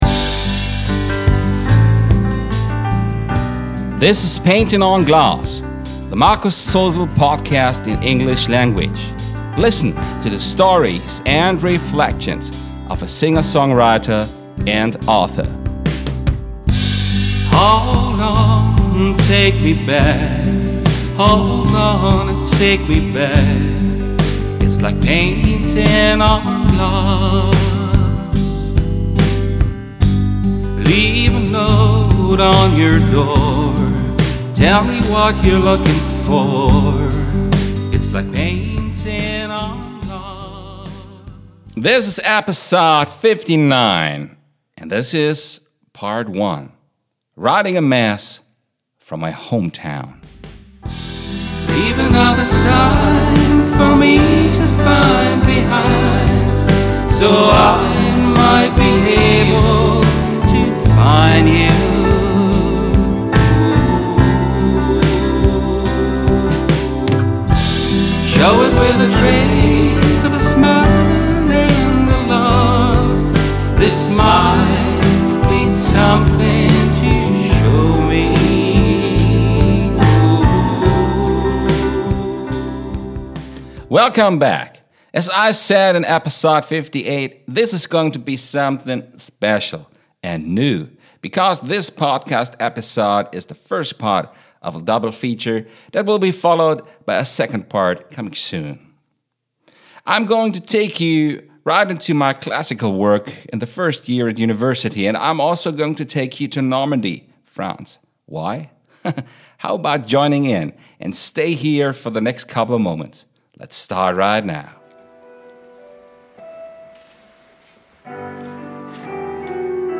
His "Mass for My Hometown" for choir and piano. This is the first of two episodes that are dedicated to this topic. This recording was taken from a live performance on January 30th, 2010 by the choir "mehrKlang" from Baunatal.